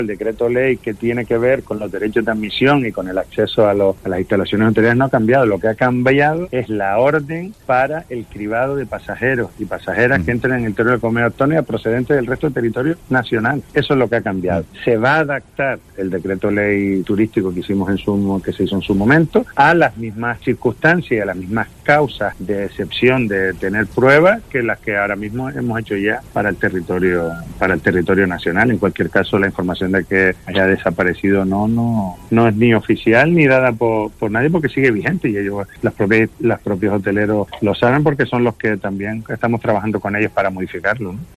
"No ha cambiado y los hoteleros lo saben, porque estamos trabajando de la mano con ello; lo que se va a hacer ahora es adaptarlo a las mismas excepciones para no tener que presentar una prueba negativa de infección", ha insistido Domínguez en una entrevista a La Mañana en Canarias.